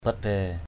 /bat-bɛ:/ (cv.) baiybat =bYbT (t.) lặt vặt = vétilles, choses de rien. ruak batbaiy r&K bT=bY ốm đau lặt vặt = avoir des malaises (sans gravité). mbeng batbaiy O$ bT=bY ăn...